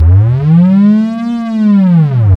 SGLBASS  4-L.wav